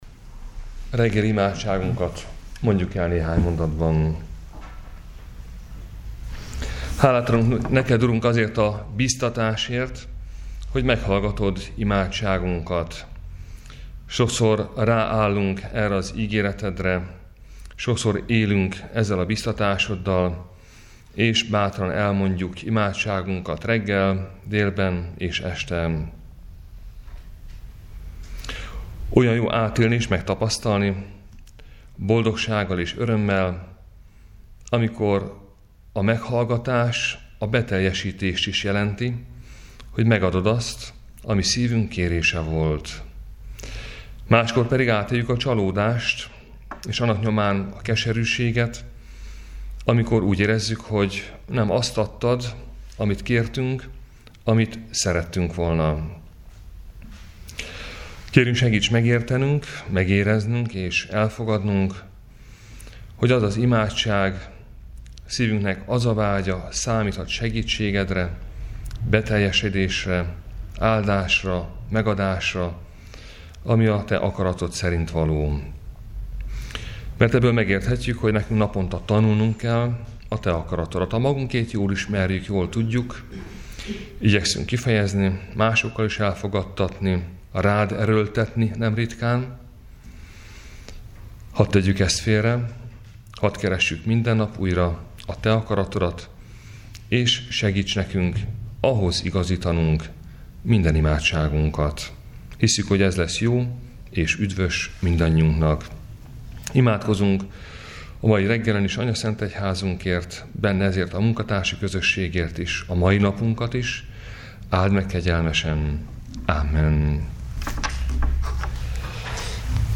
Áhítat, 2019. március 6.